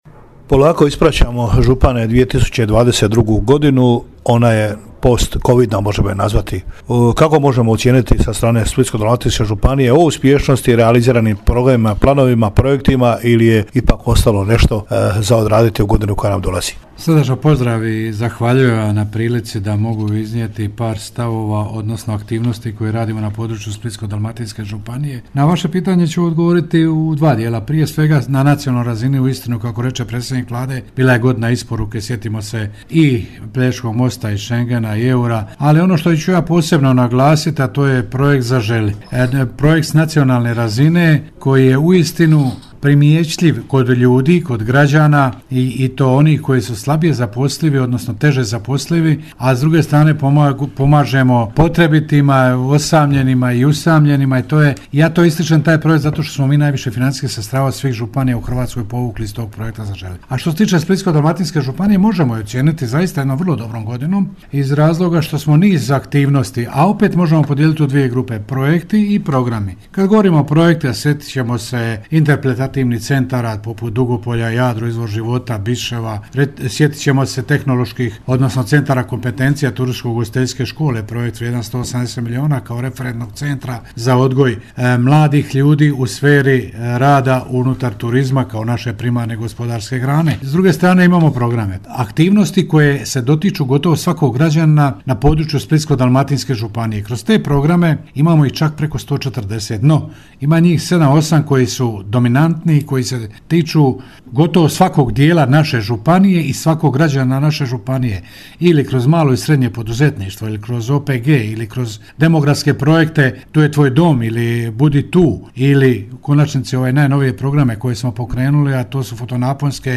Tradicionalno, u prigodi božićno-novogodišnjih blagdana, župan Blaženko Boban upriličio je primanje za predstavnike medija. Bila je to prigoda da sa splitsko-dalmatinskim županom proanaliziramo odlazeću godinu.
boban-razgovor-za-feratu-2022.mp3